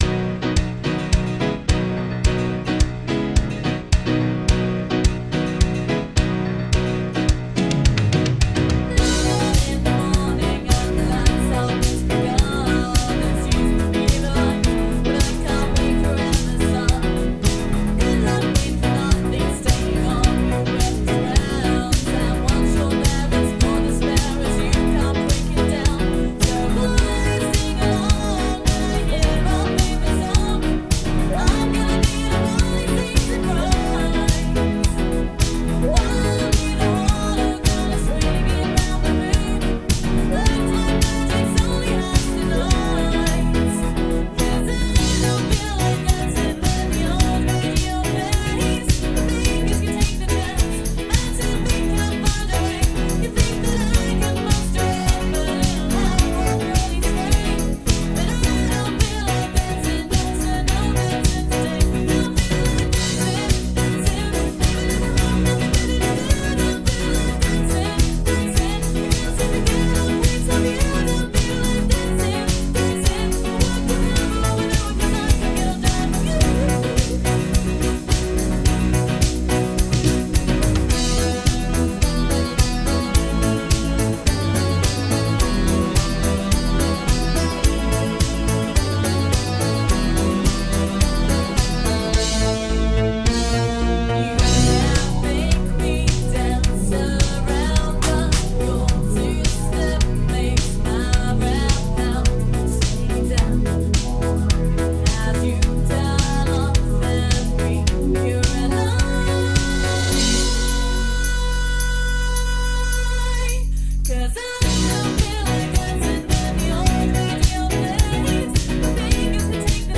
funky piano